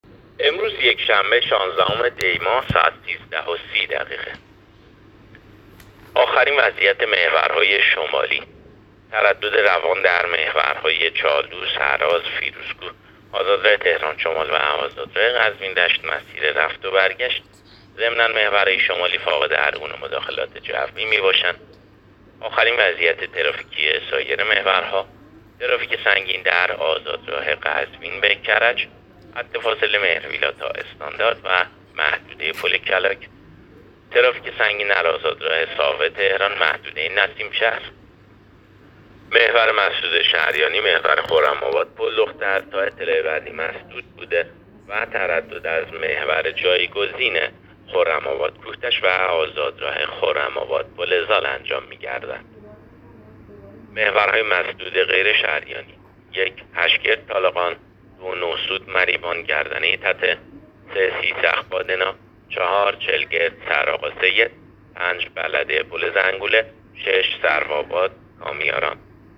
گزارش رادیو اینترنتی از آخرین وضعیت ترافیکی جاده‌ها تا ساعت ۱۳:۳۰ شانزدهم دی؛